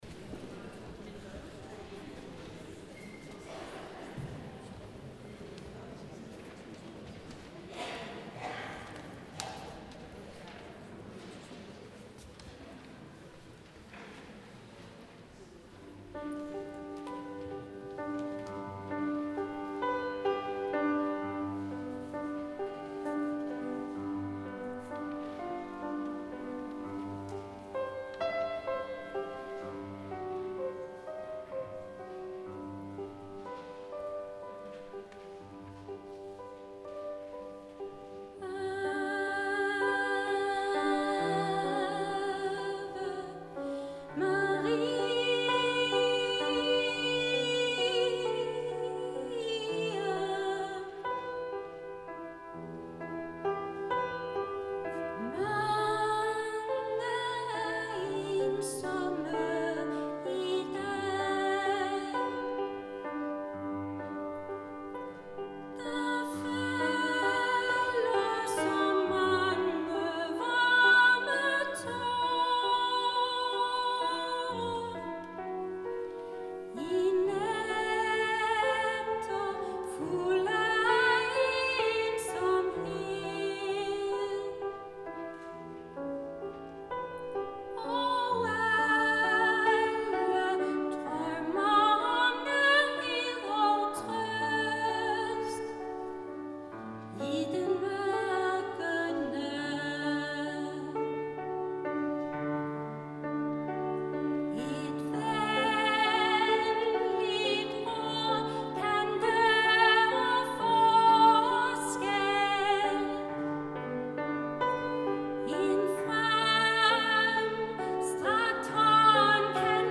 Hør julekoncerten 2022 på linket nedenfor